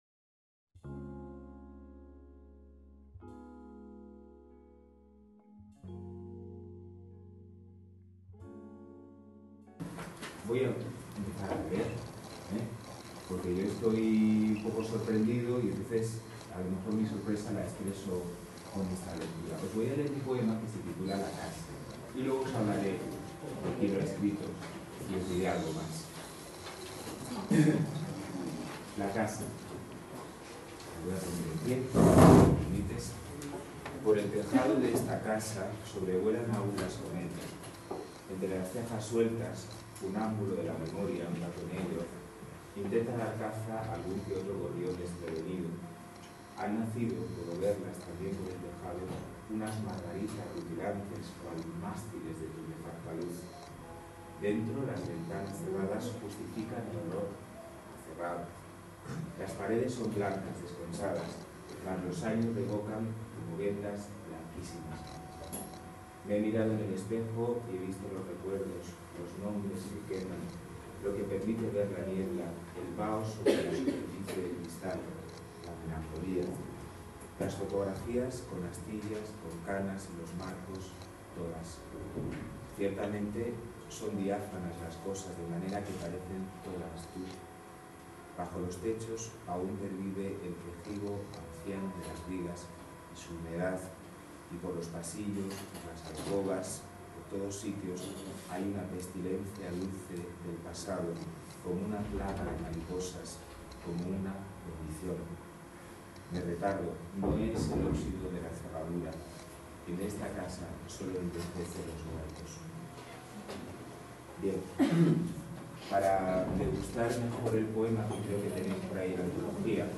Aquí os dejamos la primera hora de su intervención en un archivo de audio Podcast Tags: poemario mágico